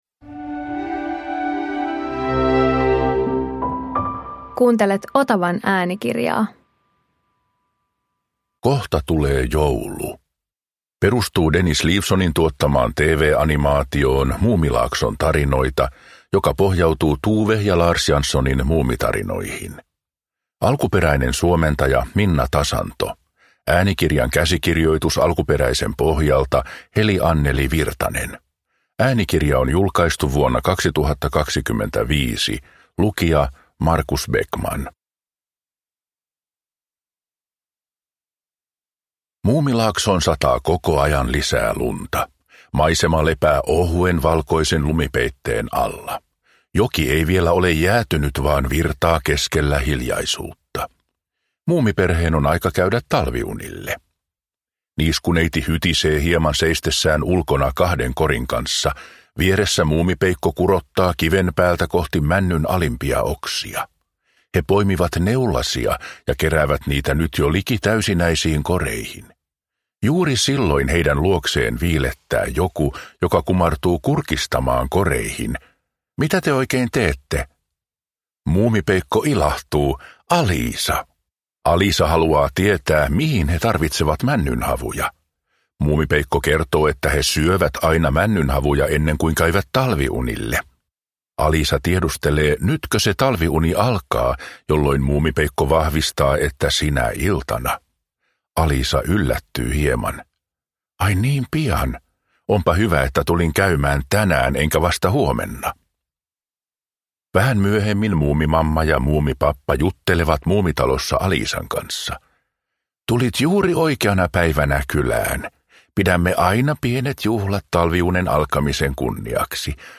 Muumi - Kohta tulee joulu – Ljudbok